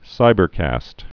(sībər-kăst)